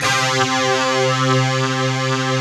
Index of /90_sSampleCDs/Optical Media International - Sonic Images Library/SI1_DistortGuitr/SI1_200 GTR`s